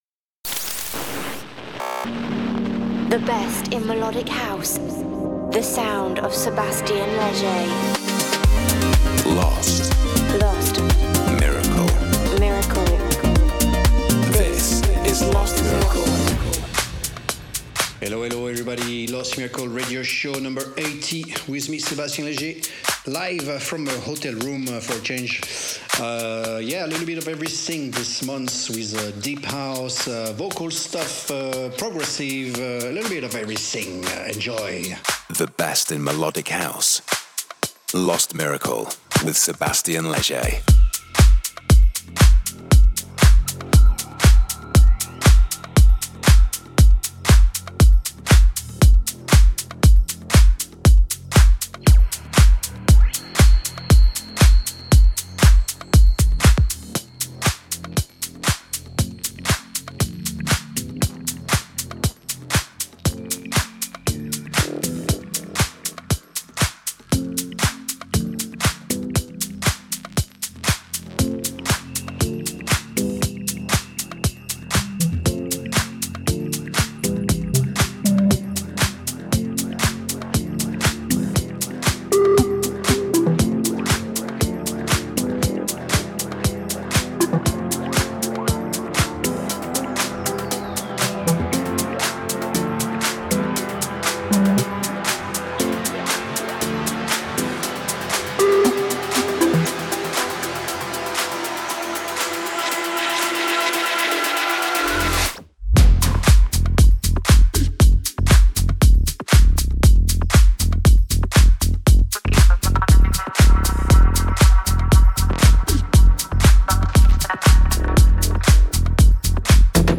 Also find other EDM Livesets, DJ Mixes and Radio Show
the monthly radio show